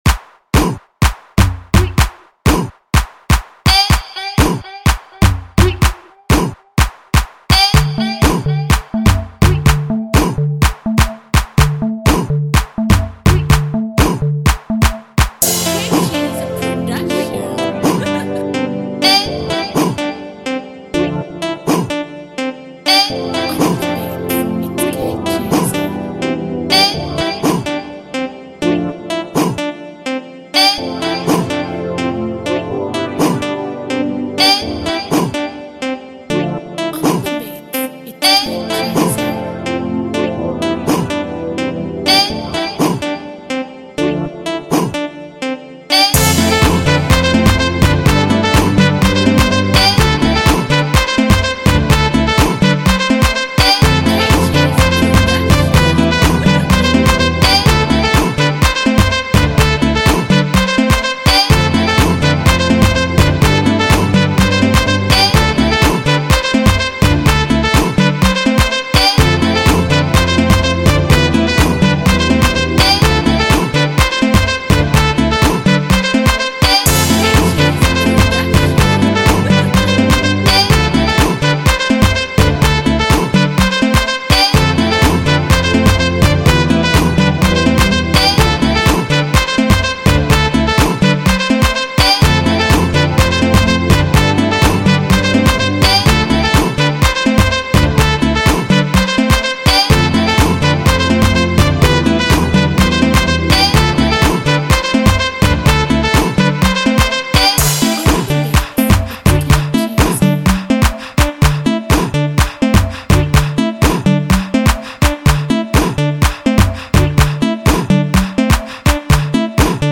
This is a kind of South African beat